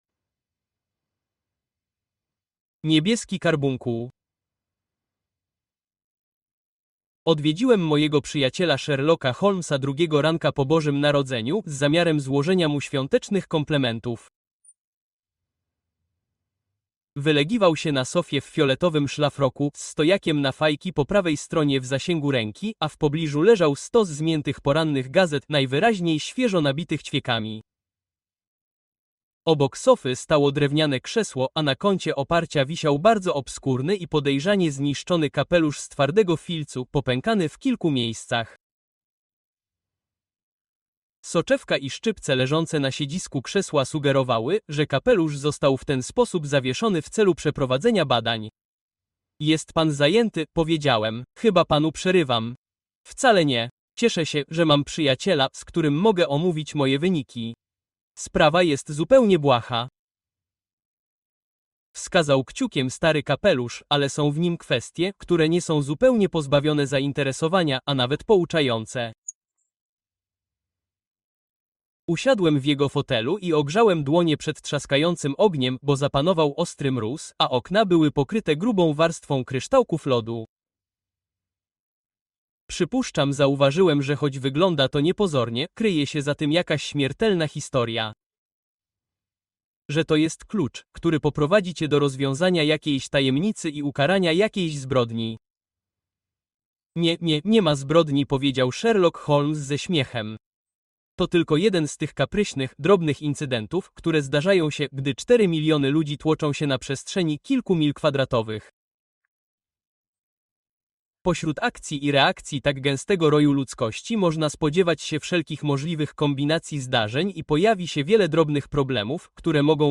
The Bruce-Partington Plans: Spy Secrets Revealed (Audiobook)